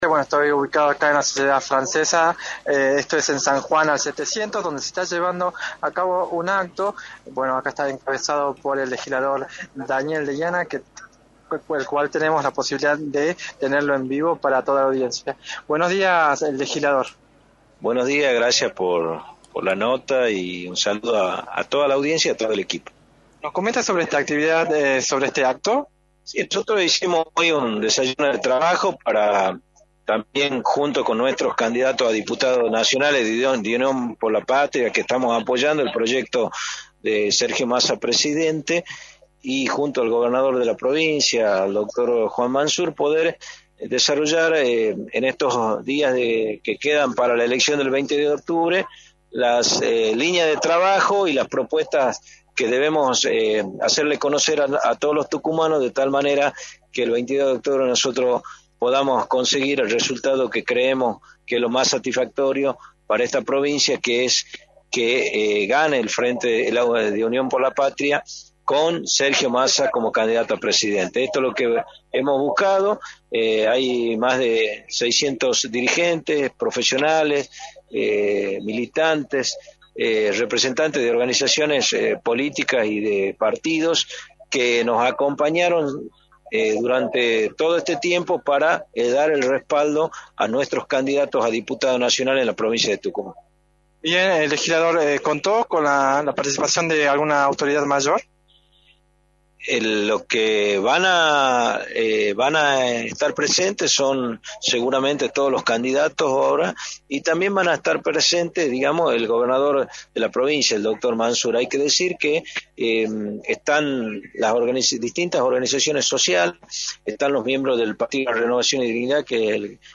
Daniel Deiana, Legislador, indicó en Radio del Plata Tucumán, por la 93.9, en qué consistió el desayuno de trabajo realizado en la Sociedad francesa en el cual participaron Juan Manzur, Gladys Medina y Pablo Yedlin, candidatos a Diputado Nacional por Unión por la Patria.
«Hay que centrarse en el objetivo de ganar el 22 de octubre y sino de llegar a la segunda vuelta, y luego será Osvaldo Jaldo el que decida quién será el que estará formando parte del gabinete» señaló Daniel Deiana en entrevista para «La Mañana del Plata», por la 93.9.